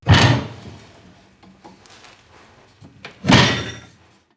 Abrir las puertas de una alacena de cristal
Sonidos: Acciones humanas
Sonidos: Hogar